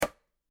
Golpe de piedra contra una calabaza
golpe
Sonidos: Acciones humanas